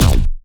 retro punch
arcade cartoon damage drum impact punch retro sound effect free sound royalty free Gaming